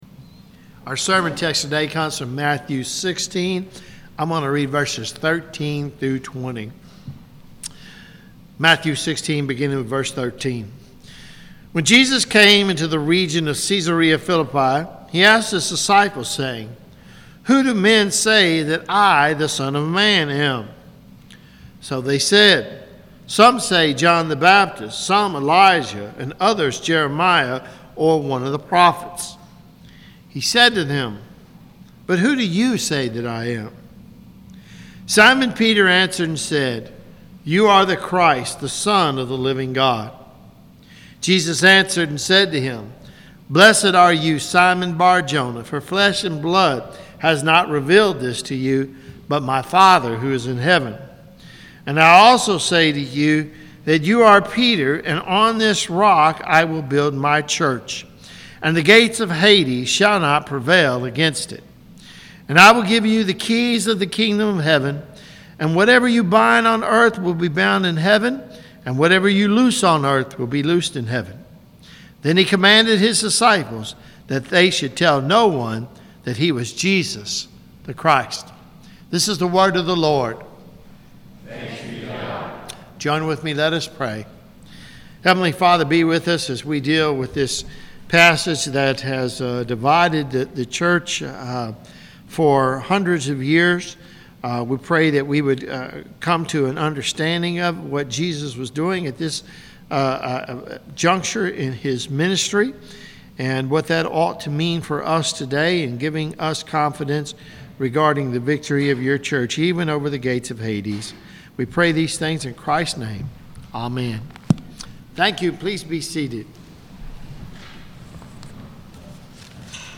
Live-streamed service available Sundays 9am until 12:30pm CST.